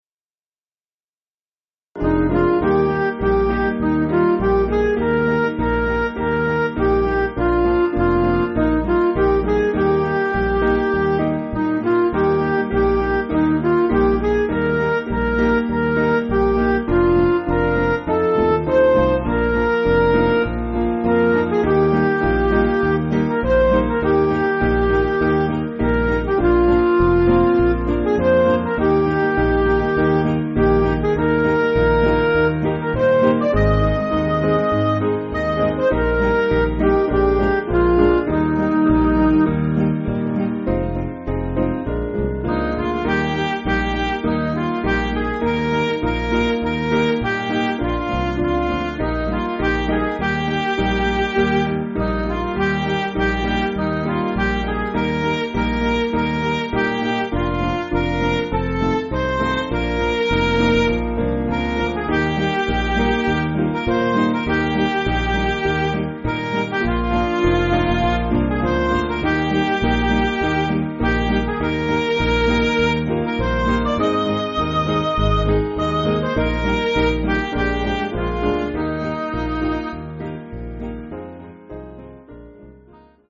Piano & Instrumental
(CM)   5/Eb